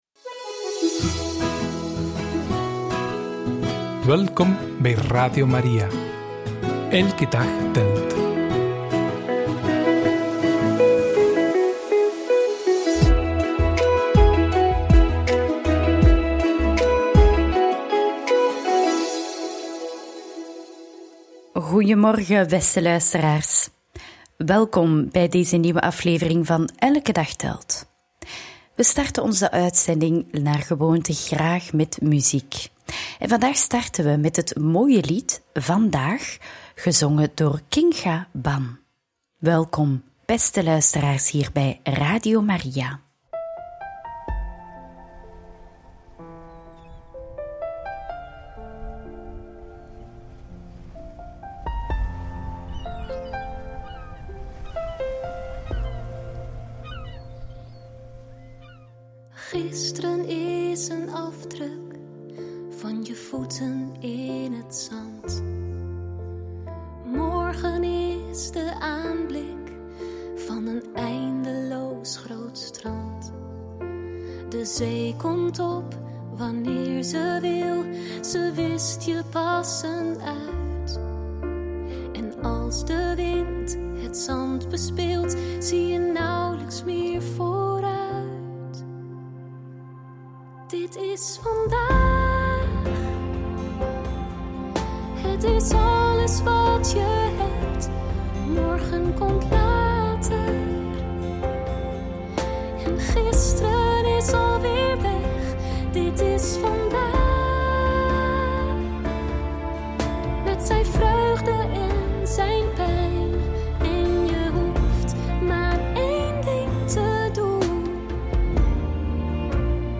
Getuigenis van een bewoonster van een WZC en vandaag feest van de geboorte van Johannes de Doper! – Radio Maria